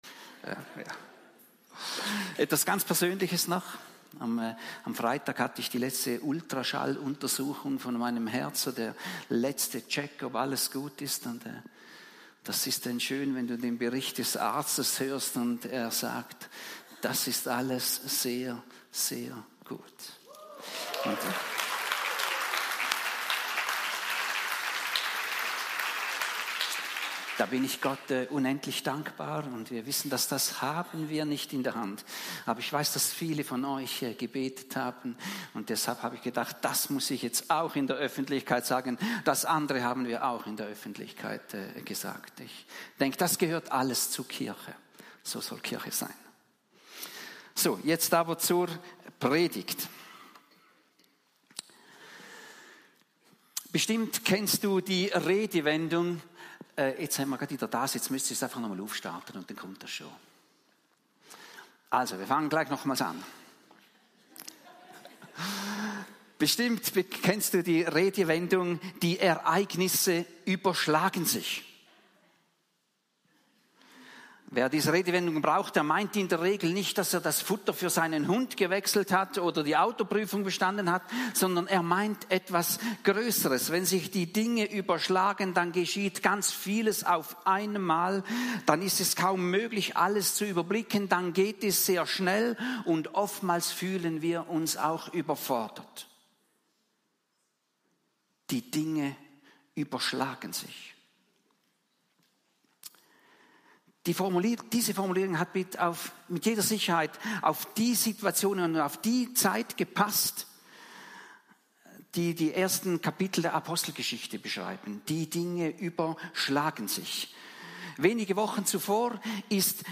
Predigten der Kirche im Rebgarten, Romanshorn